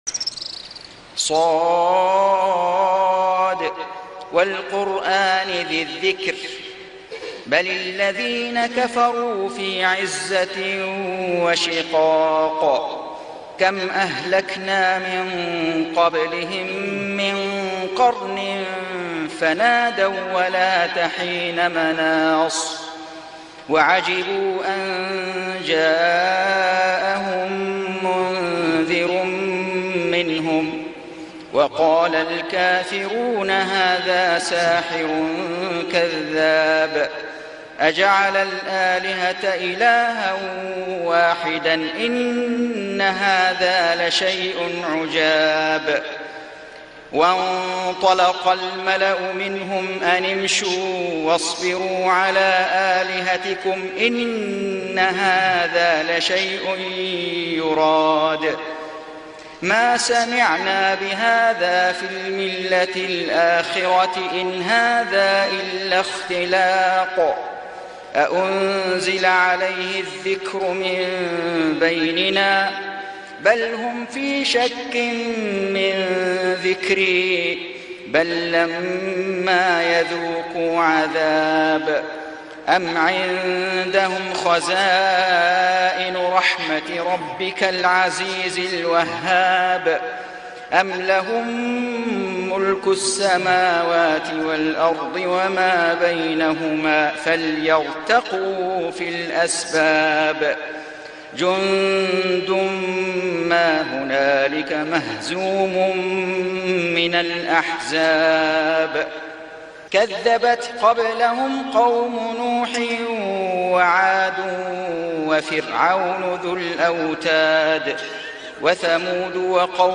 سورة ص > السور المكتملة للشيخ فيصل غزاوي من الحرم المكي 🕋 > السور المكتملة 🕋 > المزيد - تلاوات الحرمين